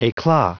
Prononciation du mot eclat en anglais (fichier audio)
Prononciation du mot : eclat